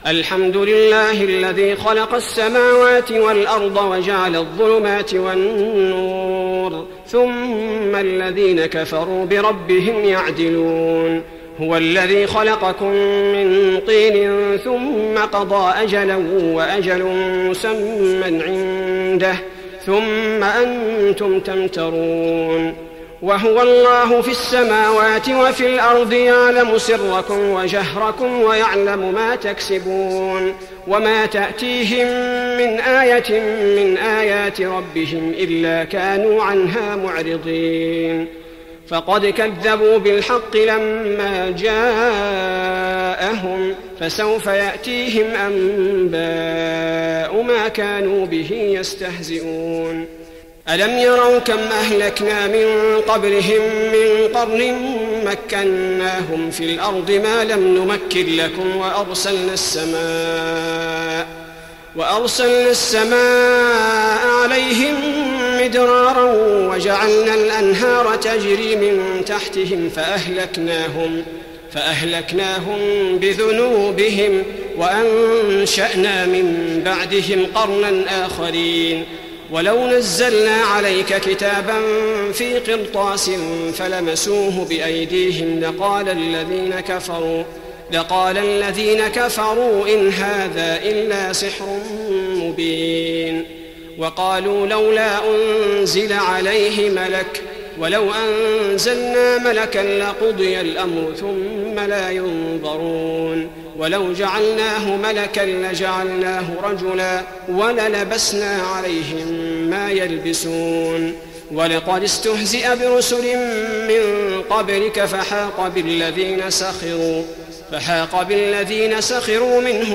تهجد رمضان 1415هـ من سورة الأنعام (1-73) Tahajjud Ramadan 1415H from Surah Al-An’aam > تراويح الحرم النبوي عام 1415 🕌 > التراويح - تلاوات الحرمين